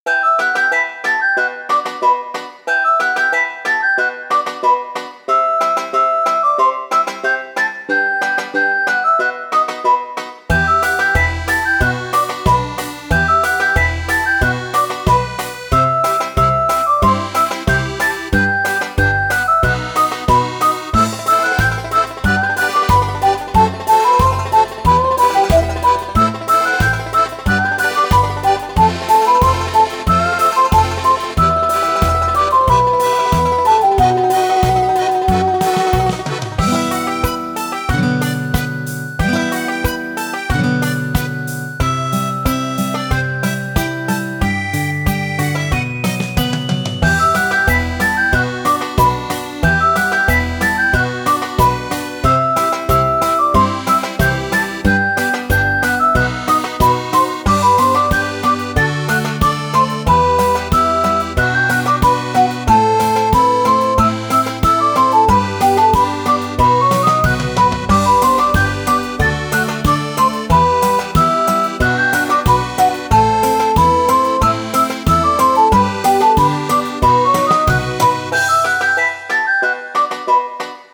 イメージ：異国情緒 郷愁   カテゴリ：RPG−街・村・日常